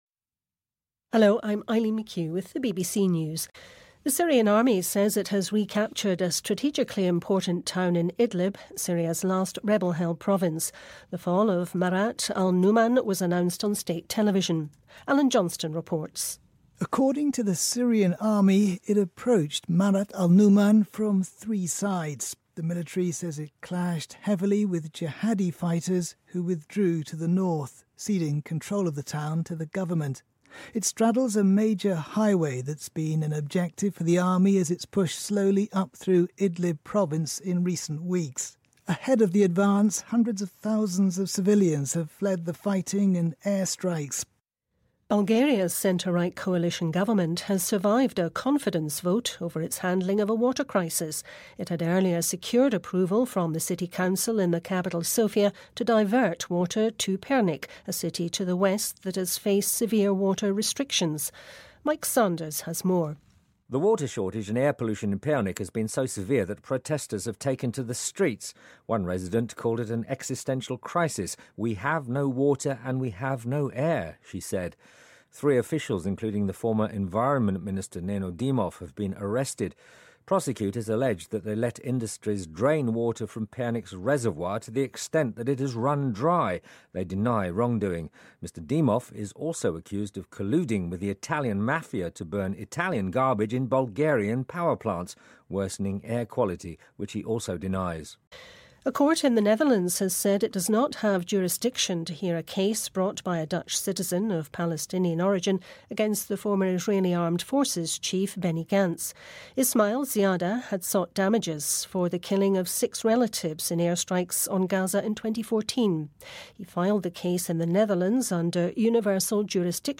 News
英音听力讲解:叙利亚军重新夺回战略重镇